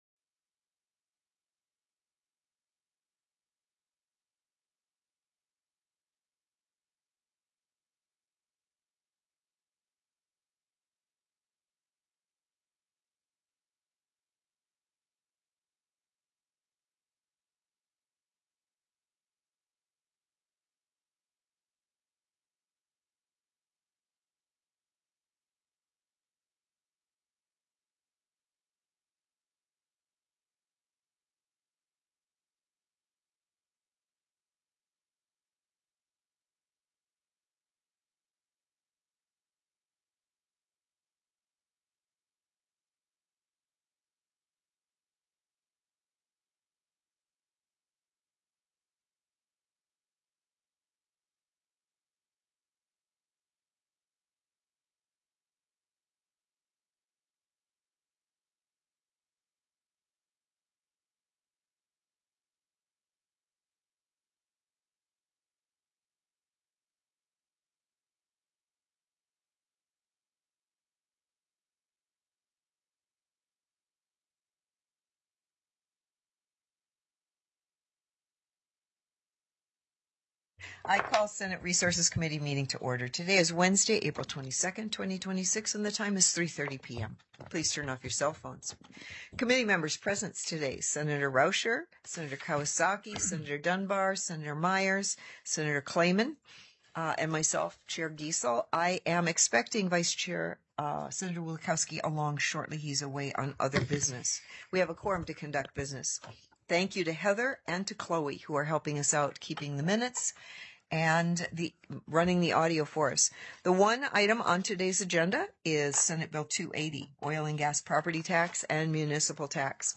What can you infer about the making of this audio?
The audio recordings are captured by our records offices as the official record of the meeting and will have more accurate timestamps. SB 280 OIL & GAS PROPERTY TAX; MUNI TAX TELECONFERENCED